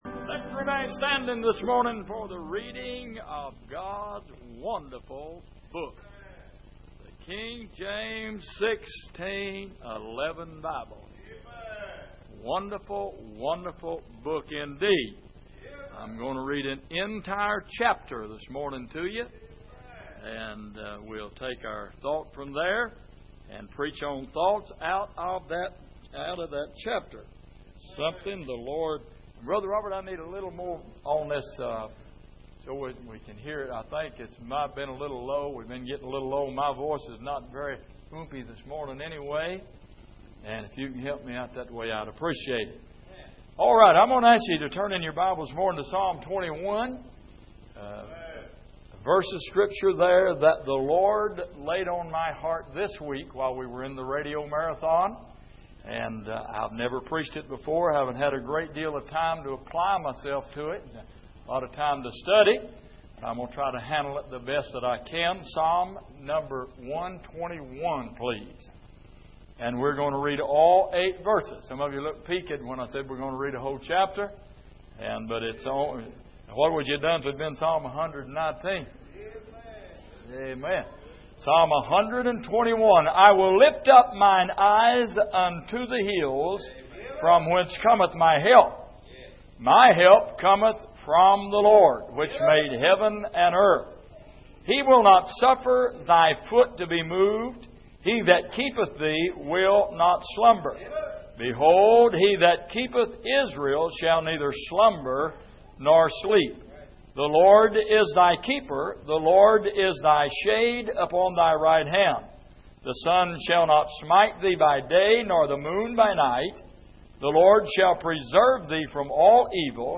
Passage: Psalm 121:1-8 Service: Sunday Morning